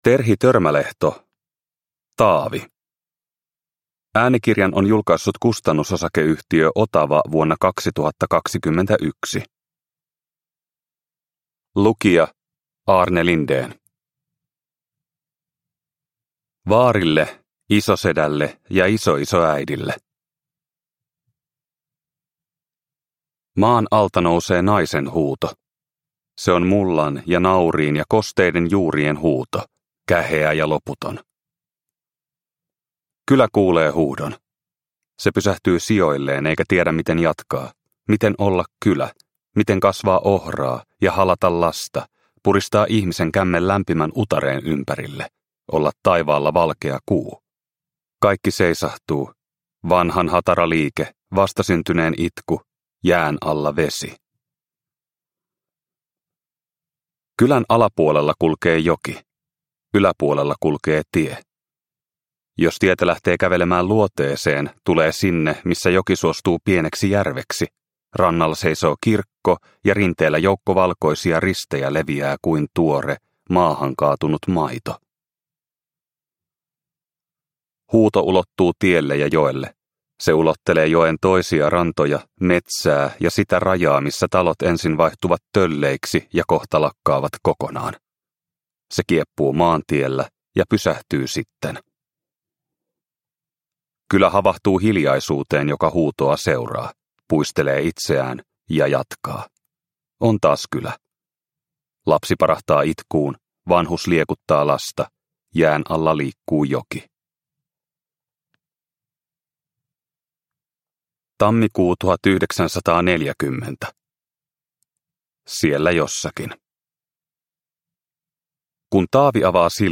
Taavi – Ljudbok – Laddas ner